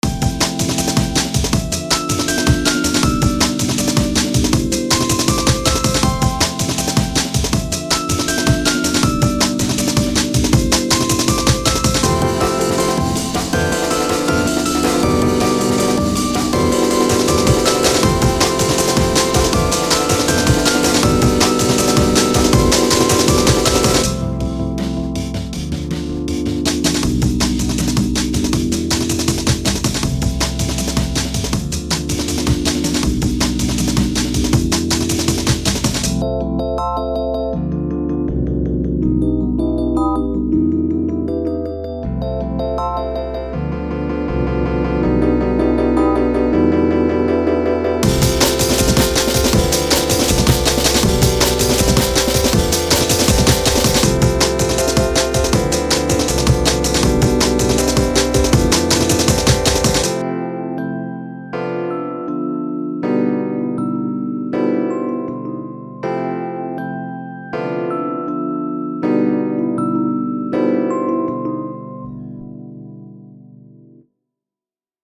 ominous and space-y with drums